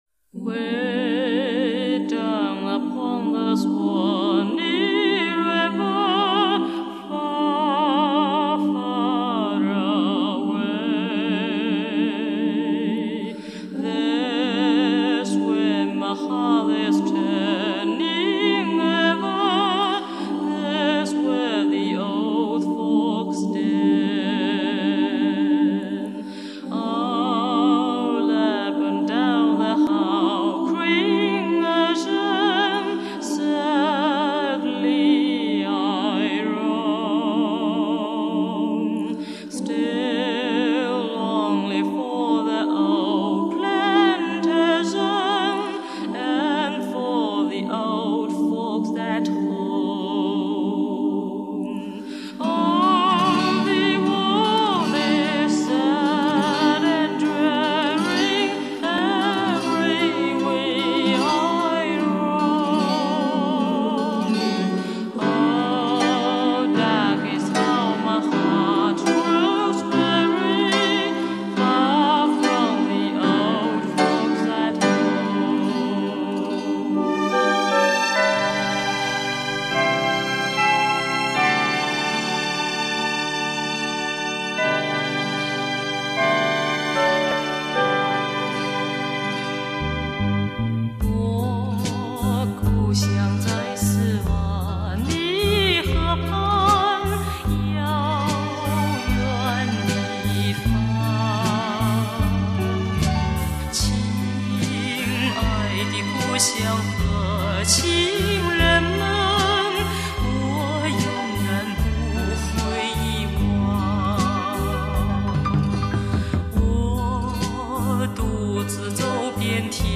珍贵的80-90年代录音  大陆著名歌唱家外国歌曲合辑